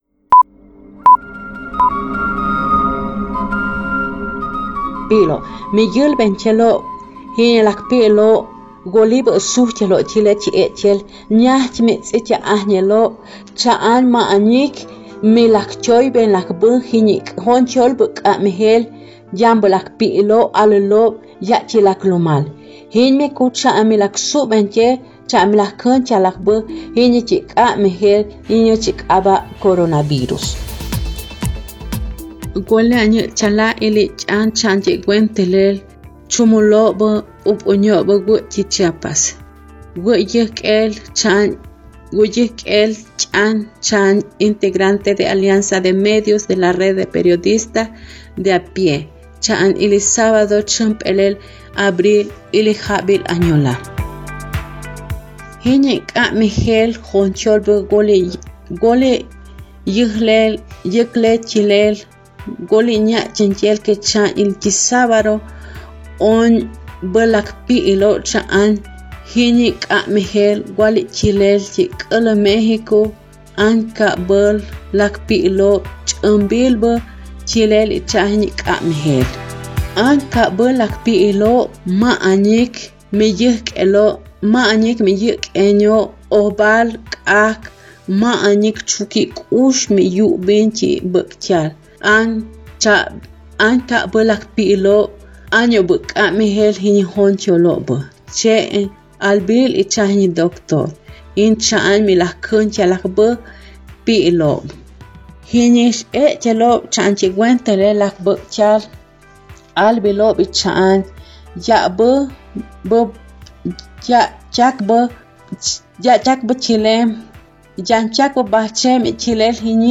Traducción y locución
capsula-no2-chiapasparalelo-convid19-chol.mp3